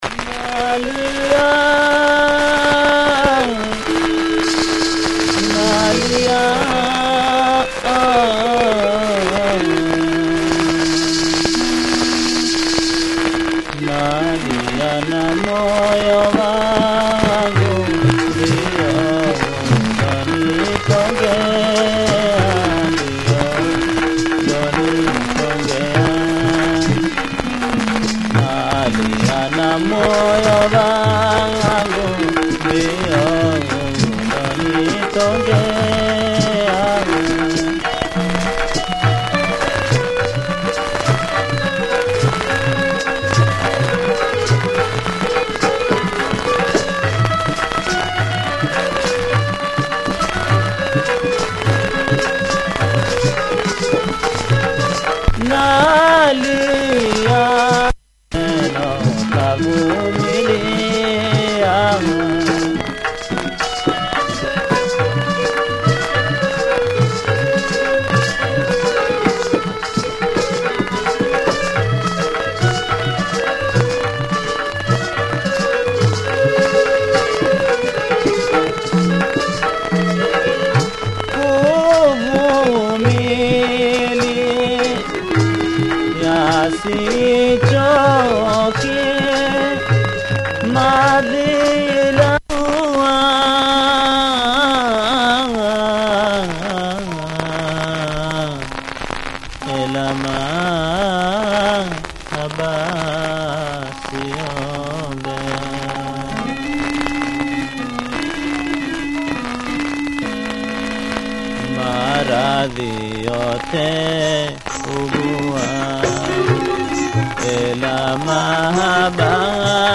Taarab